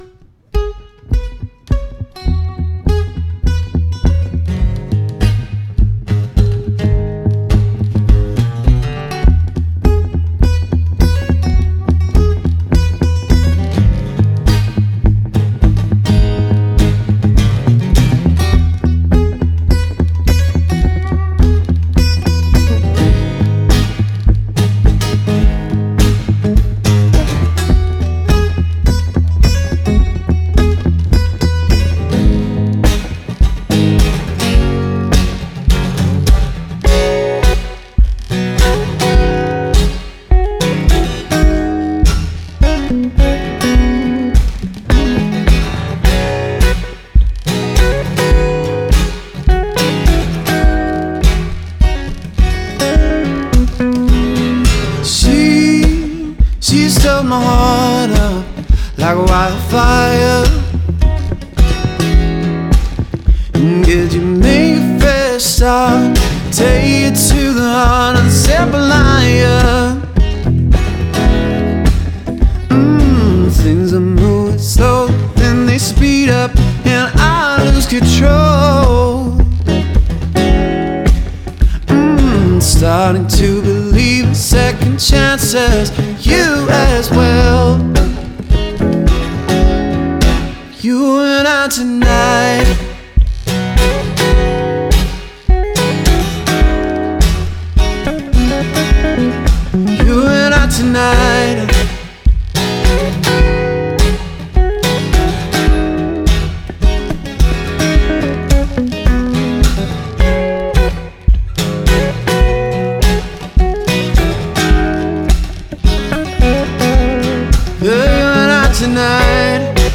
We're an eclectic mix of musicians who play indie-style music influenced by funk, rock, reggae, and jazz.
1) A "demo" of our lead singer and guitarist doing one of our songs. IT'S NOT THE FULL BAND (we're working on our first recording!) but it will give you an idea of what we sound like.